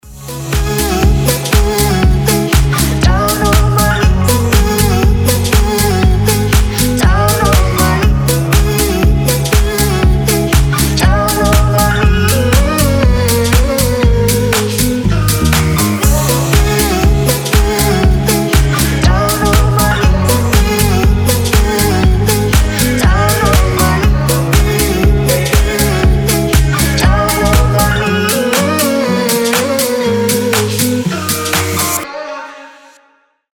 • Качество: 320, Stereo
гитара
Electronic
Dance Pop
красивый женский голос
house
Приятная и теплая музычка